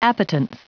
Prononciation du mot appetence en anglais (fichier audio)
Prononciation du mot : appetence